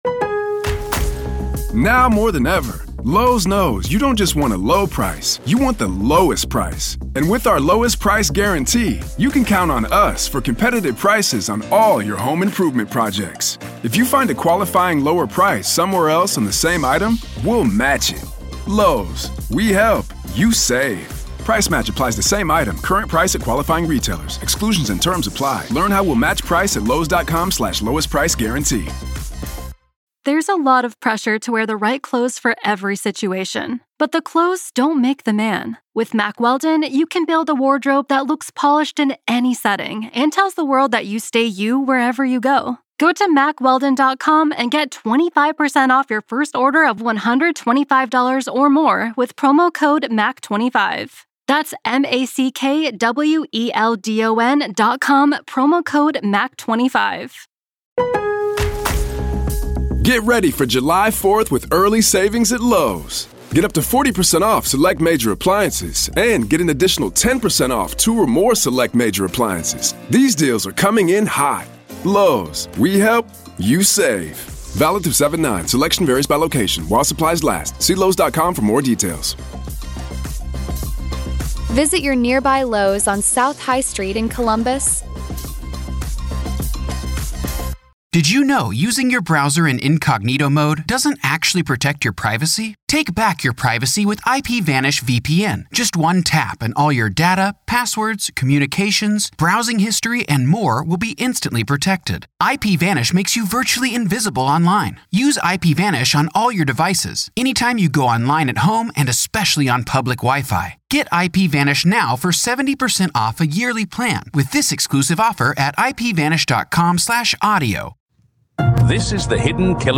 If you’ve ever wondered how evil hides in plain sight, this is a conversation you don’t want to miss.